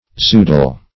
Zooidal \Zo*oid"al\